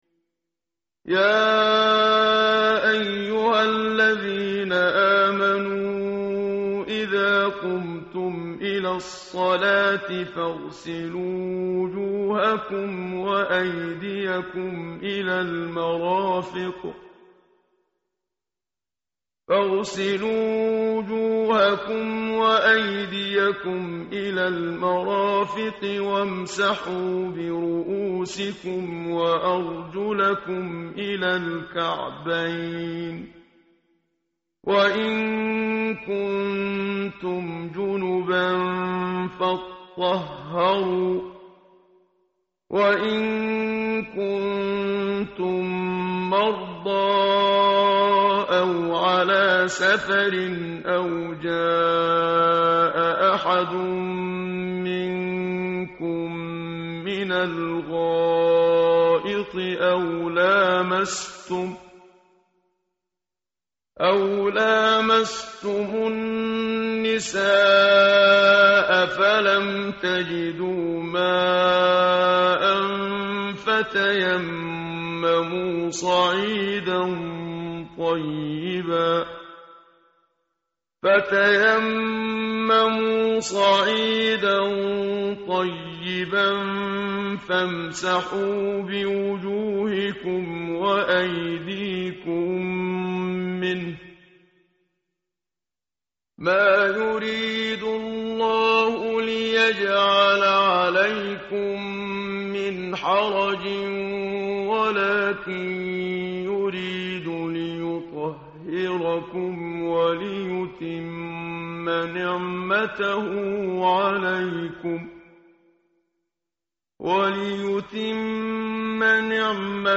متن قرآن همراه باتلاوت قرآن و ترجمه
tartil_menshavi_page_108.mp3